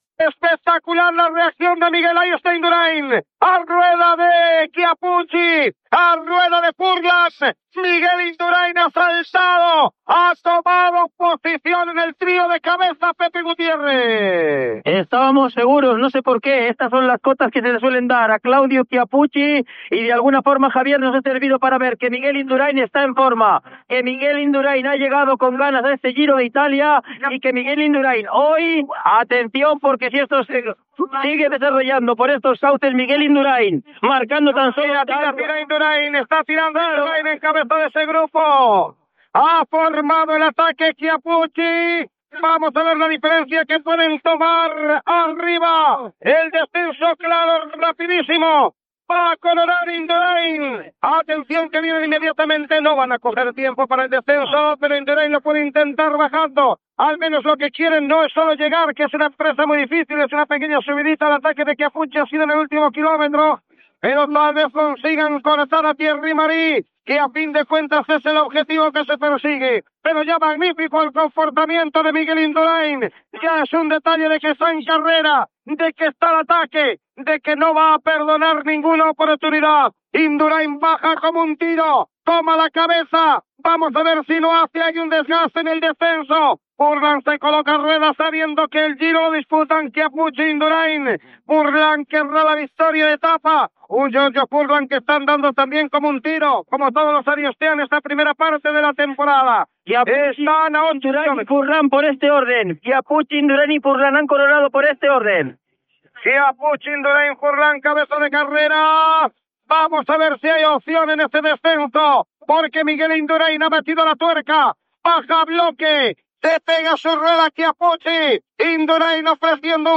Narració de la tercera etapa del Giro d'Itàlia, Uliveto Terme-Arezzo. Escapada de Miguel Induráin amb Claudio Chiappucci
Esportiu
FM